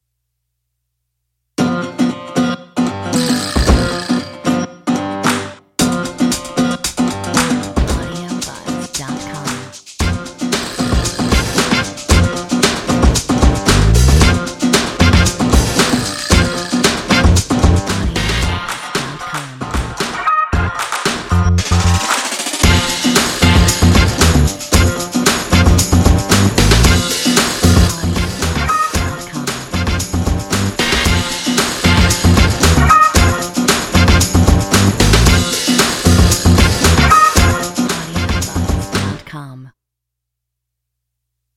Metronome 114